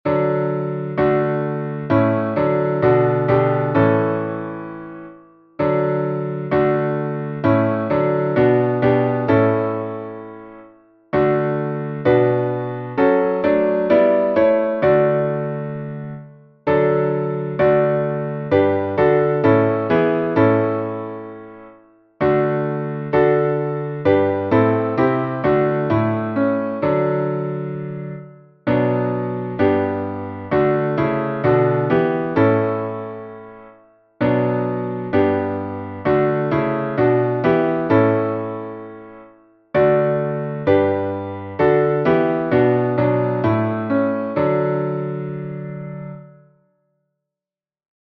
Modo: jônio
salmo_150A_instrumental.mp3